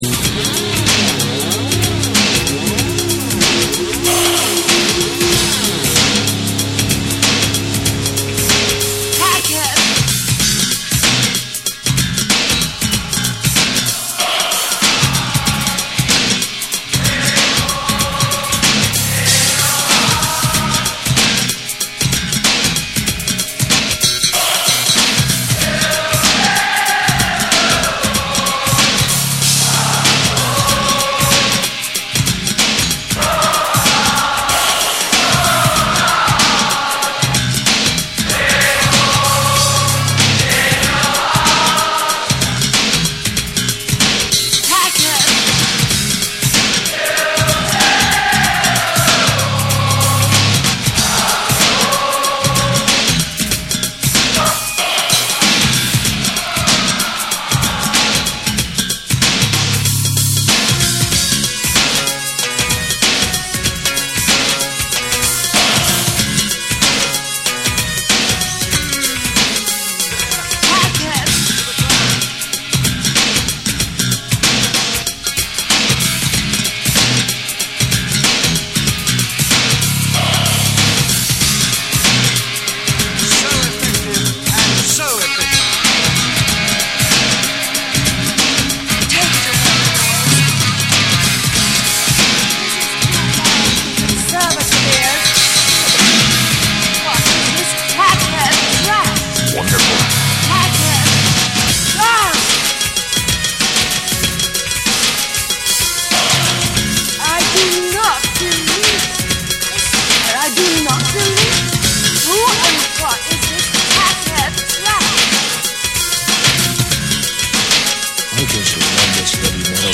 88年ニューヨークのRitzで行われたライブ音源を収録。
NEW WAVE & ROCK